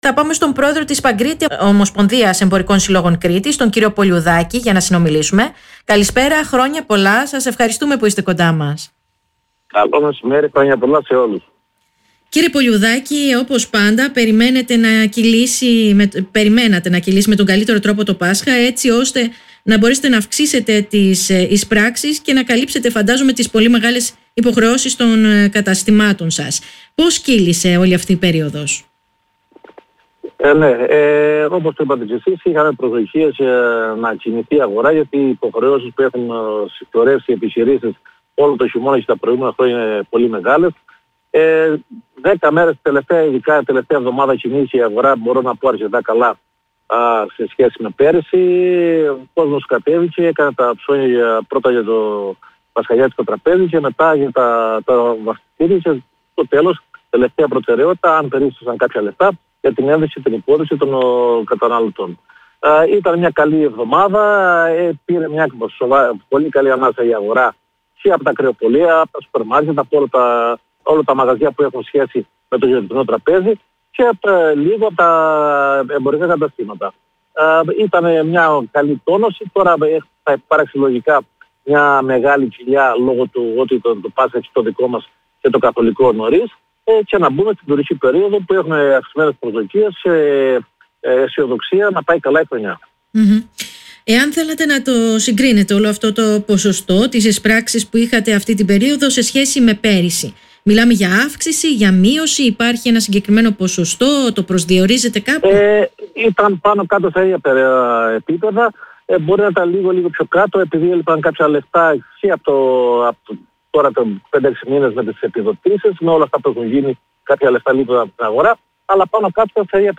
στο κεντρικό δελτίο ειδήσεων του Politica 89,8